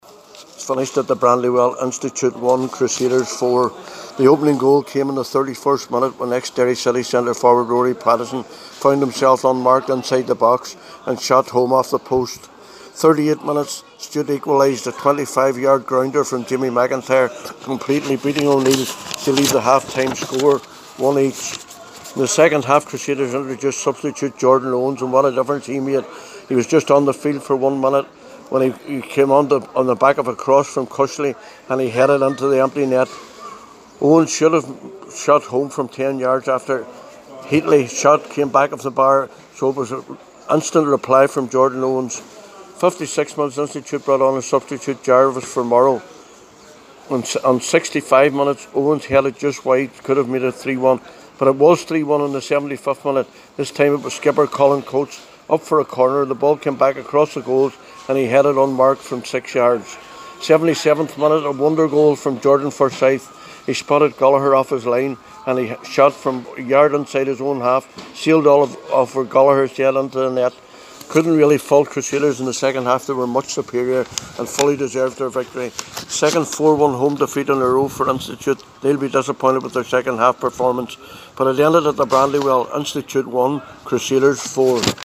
reports for Highland Radio…